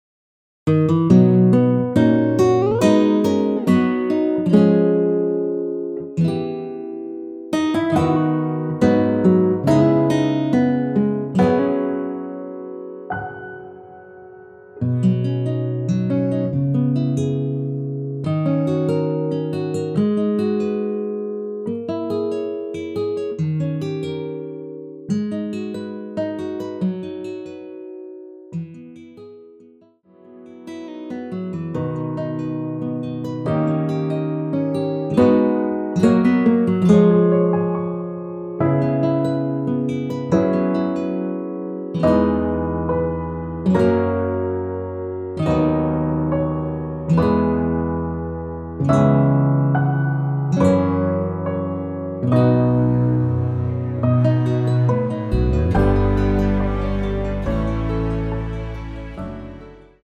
원키에서(-6)내린 (1절+후렴)MR입니다.
앞부분30초, 뒷부분30초씩 편집해서 올려 드리고 있습니다.
중간에 음이 끈어지고 다시 나오는 이유는